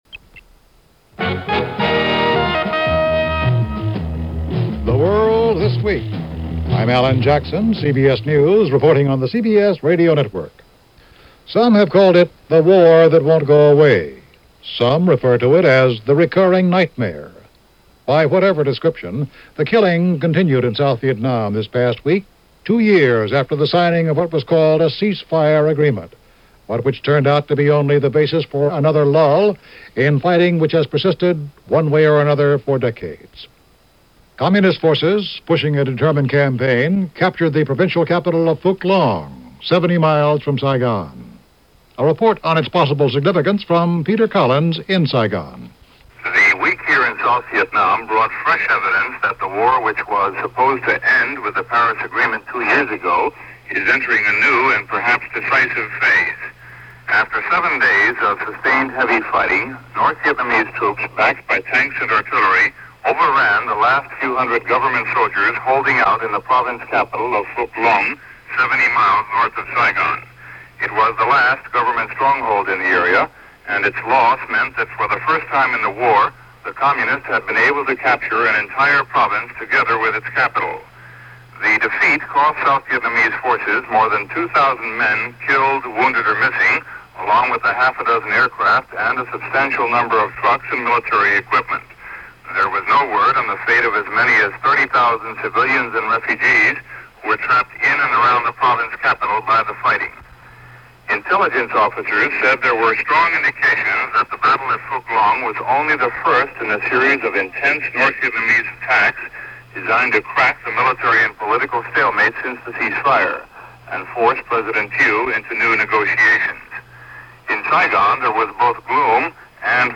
And so it went, and so it kept going – Unemployment and news for the week ending January 11, 1975 as reported on the CBS Radio program The World This Week.